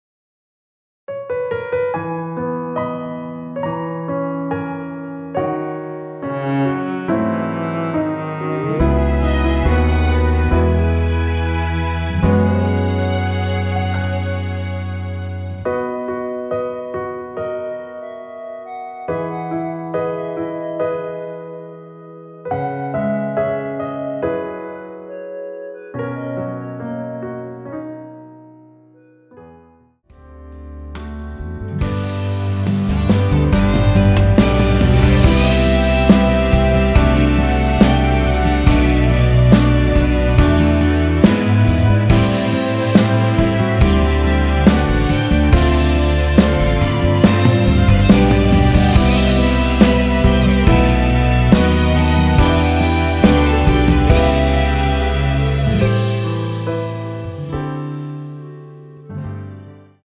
원키 멜로디 포함된 MR입니다.(미리듣기 확인)
앨범 | O.S.T
앞부분30초, 뒷부분30초씩 편집해서 올려 드리고 있습니다.
중간에 음이 끈어지고 다시 나오는 이유는